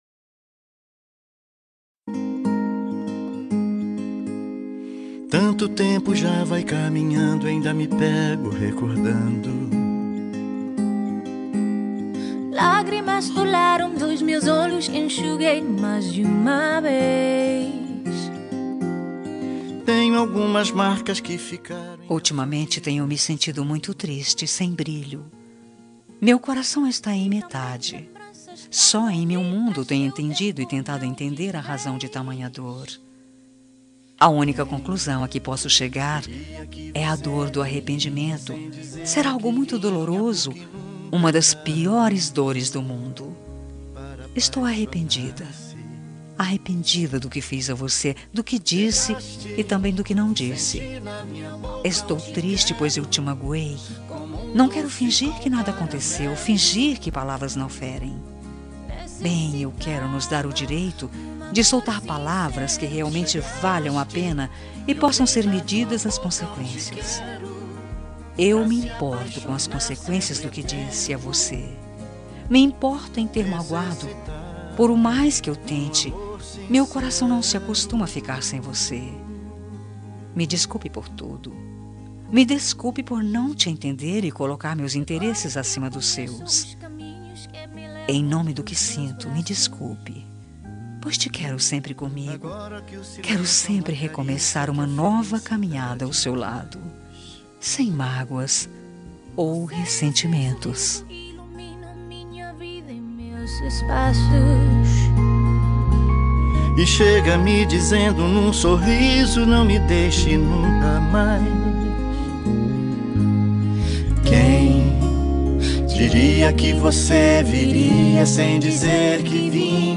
Telemensagem de Desculpas – Voz Feminina – Cód: 20187 – Dor do Arrependimento